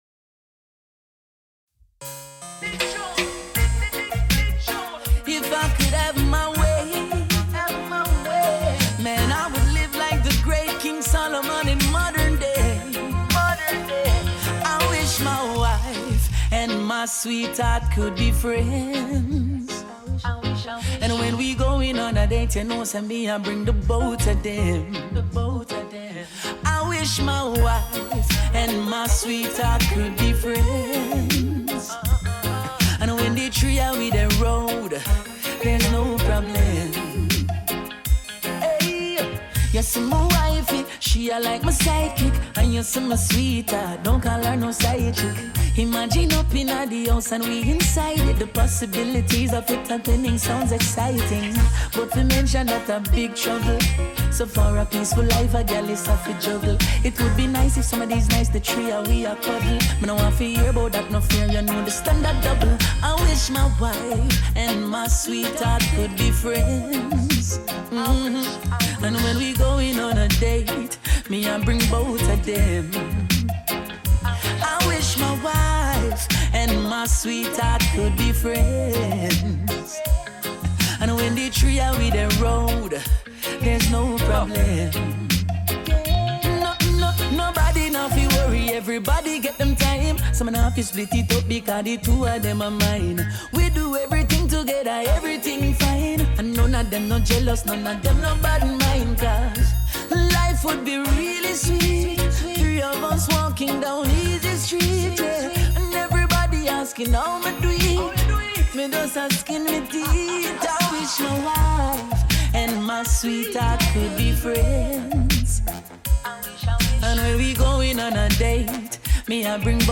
* Track aus Video extrahiert.